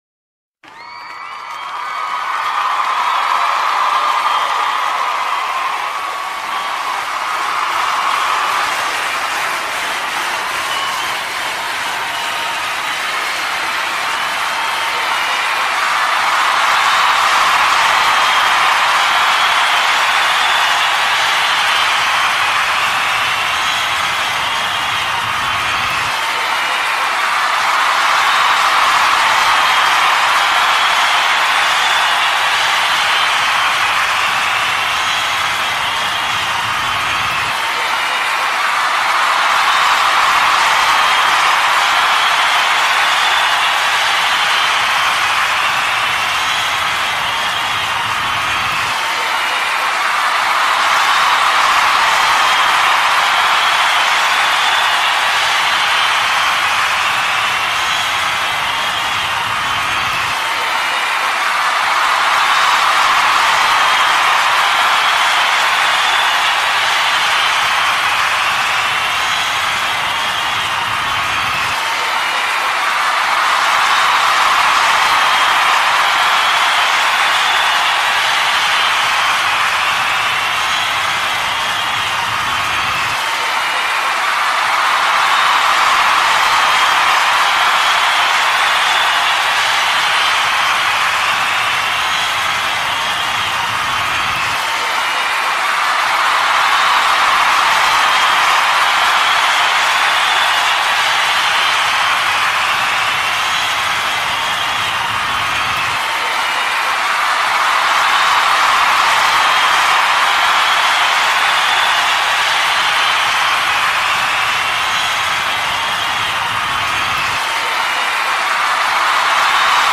جلوه های صوتی
دانلود صدای دست و شادی از ساعد نیوز با لینک مستقیم و کیفیت بالا
برچسب: دانلود آهنگ های افکت صوتی انسان و موجودات زنده دانلود آلبوم صدای دست جیغ و هورا از افکت صوتی انسان و موجودات زنده